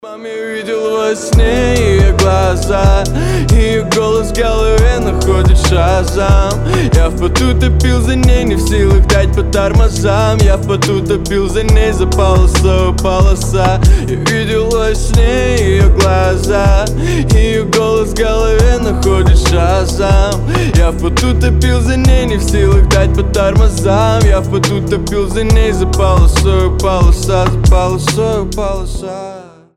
мужской вокал
лирика
спокойные